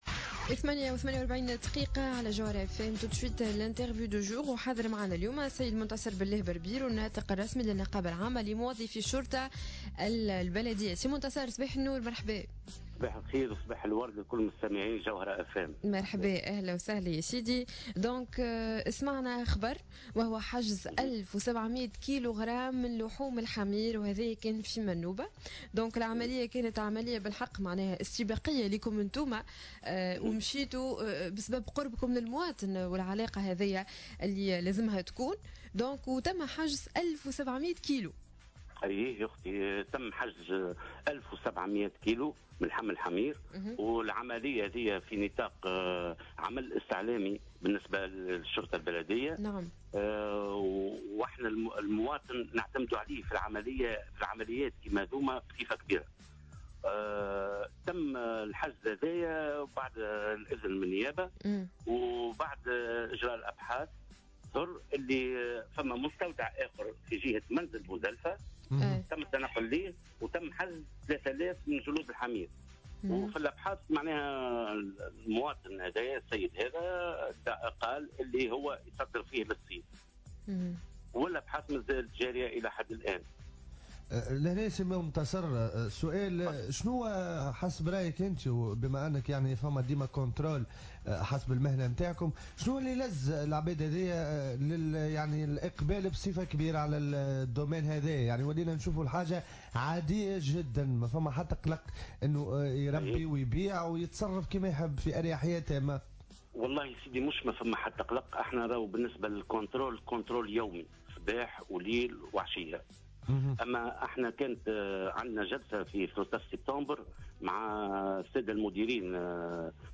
في مداخلة له على جوهرة "اف ام" صباح اليوم الثلاثاء